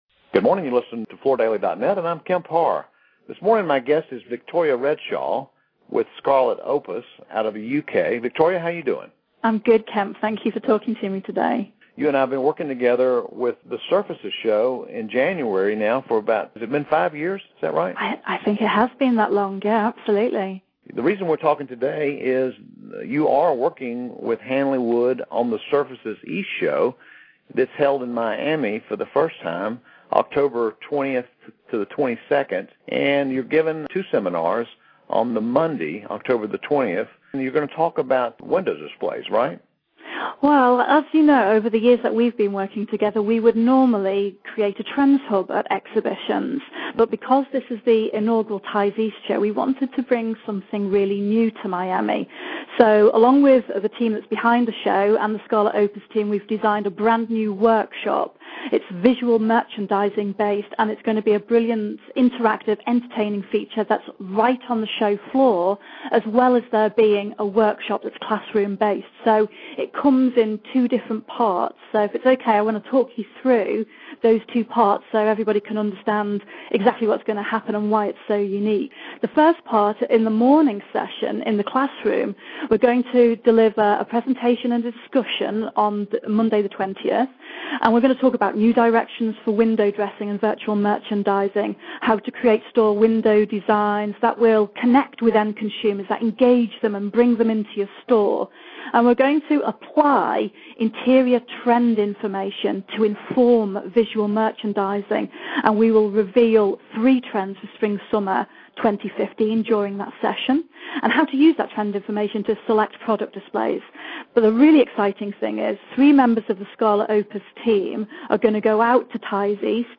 Listen to the interview to hear about this fun event and how it helps retailers attract more customers.